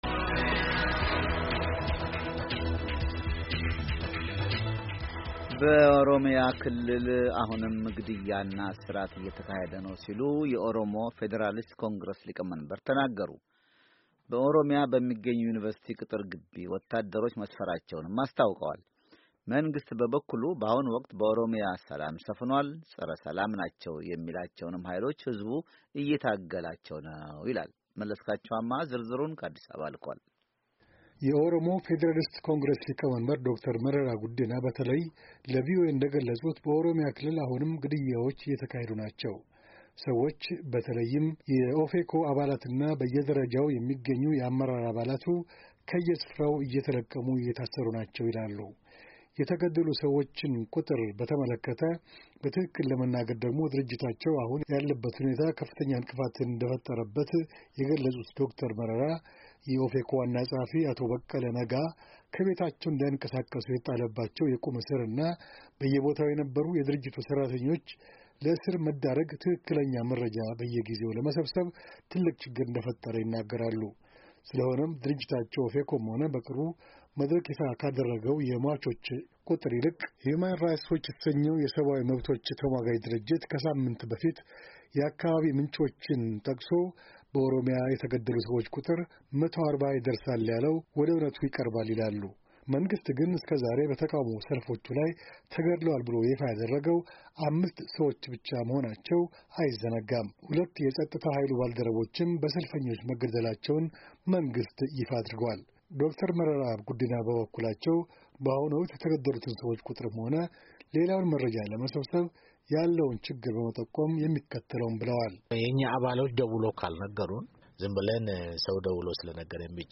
ከአዲስ አበባ ዘገባ ልኳል